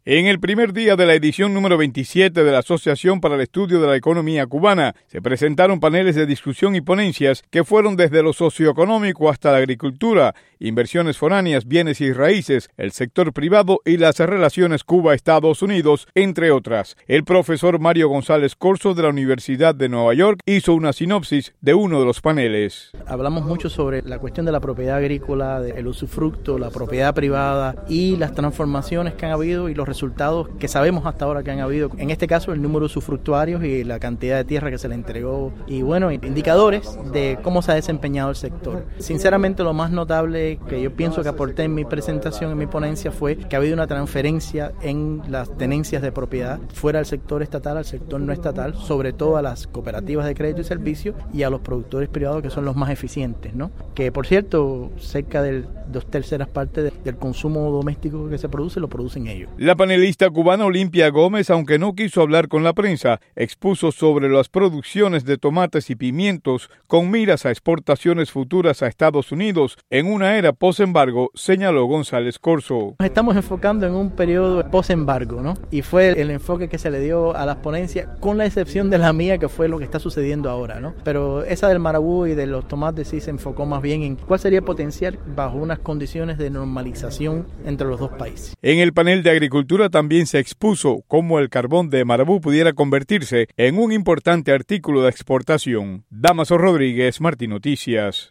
Reportaje sobre ASCE